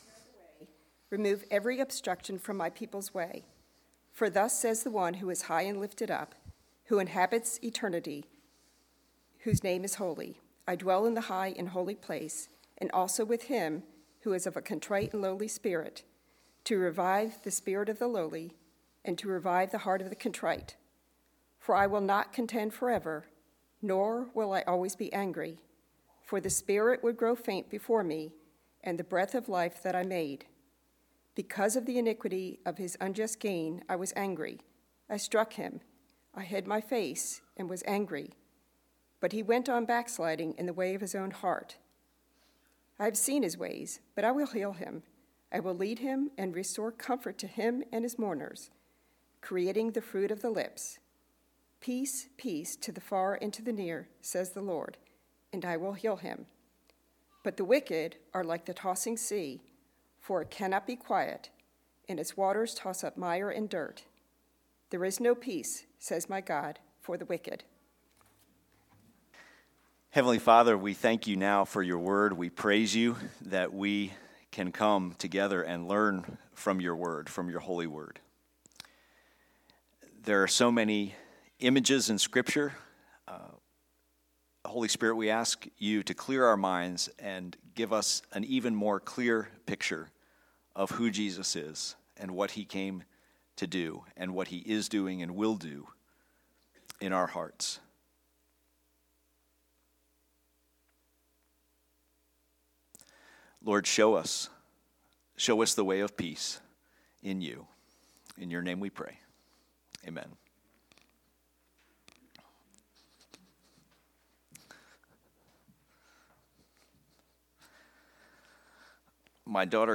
Message from Isaiah 57:14-21